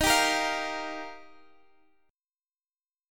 D#add9 chord